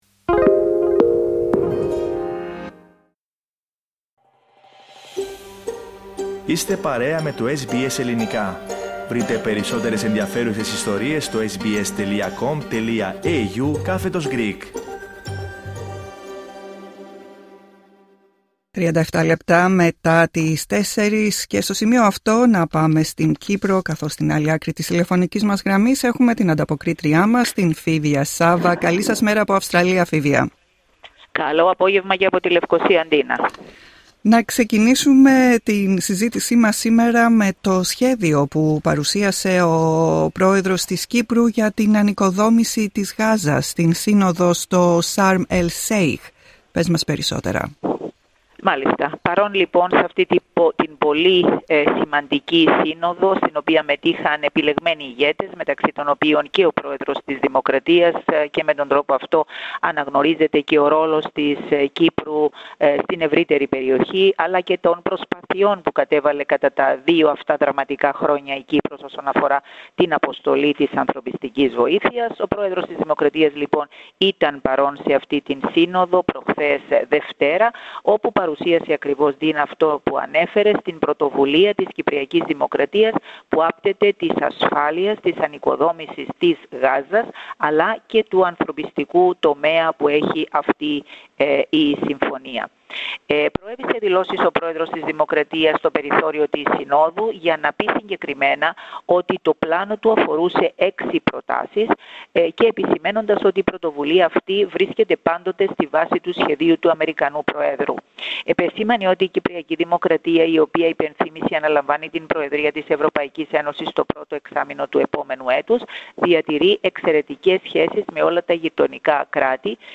Ανταπόκριση-Κύπρος: Πλάνο 6 σημείων για τη Γάζα προτείνει ο Ν. Χριστοδουλίδης